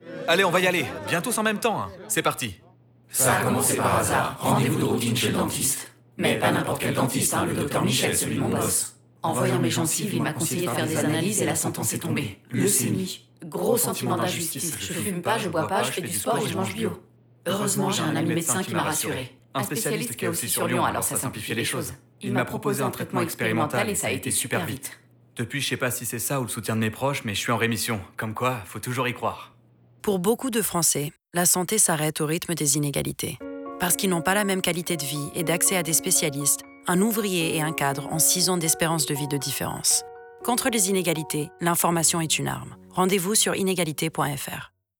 Grâce à l’écriture de trois spots radio d’un genre totalement inédit : chacun raconte une histoire portée par les voix de 10 comédiens représentatifs de la société française.
Le travail du son, orchestré par le studio O’Bahamas, a fait l’objet d’une attention toute particulière pour faire ressortir les caractéristiques de chacune des voix même lors des choeurs.
Spot radio 1